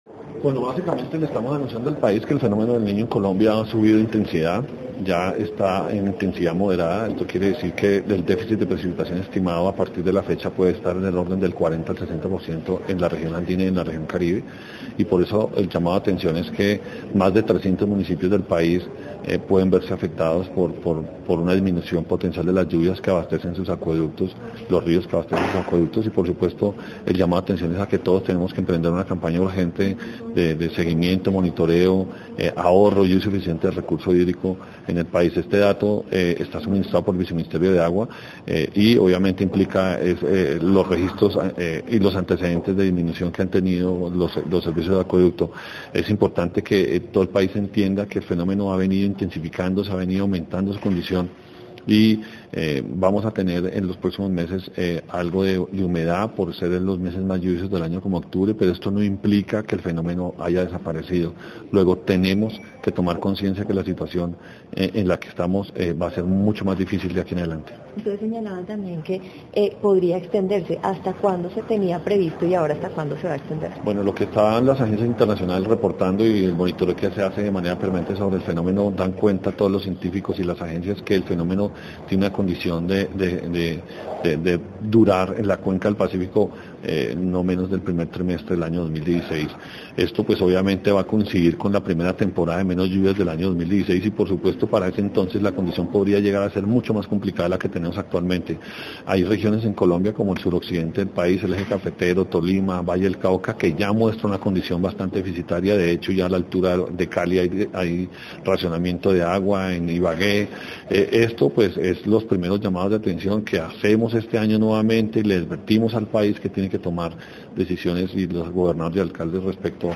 Declaraciones del Director del IDEAM, Omar Franco Torres audio